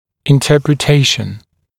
[ɪnˌtɜːprɪ’teɪʃn][инˌтё:при’тэйшн]интерпретация